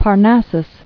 [Par·nas·sus]